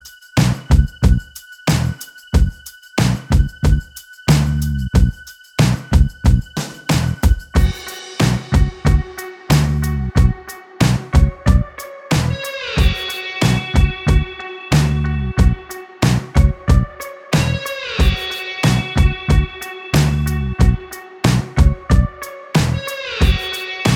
Minus Lead Guitar Indie / Alternative 2:44 Buy £1.50